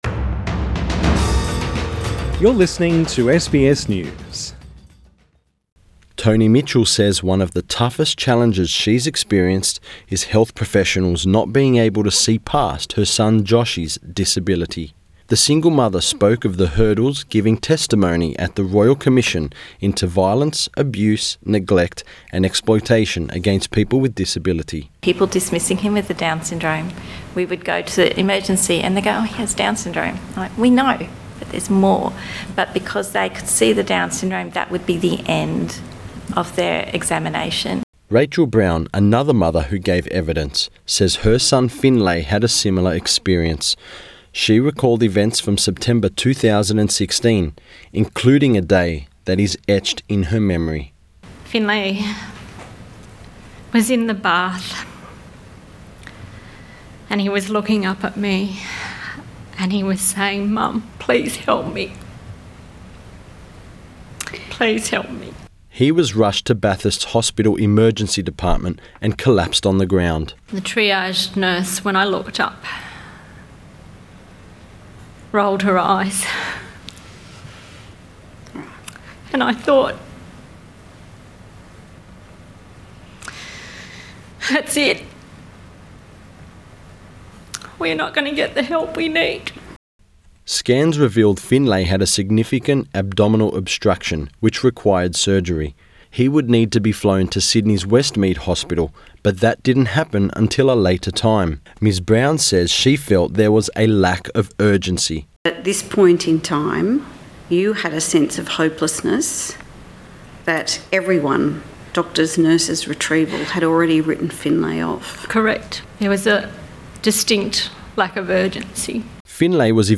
Mother gives emotional evidence at Disability Royal Commission
Mothers of down syndrome children give emotional evidence at the Disability Royal Commission in Sydney.